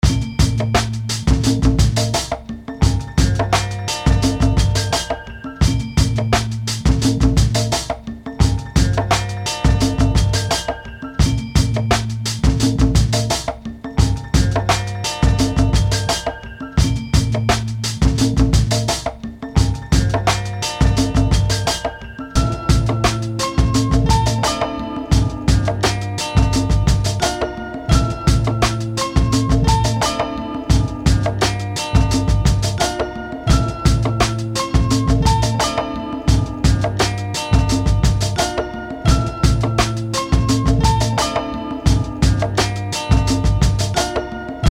Music
beat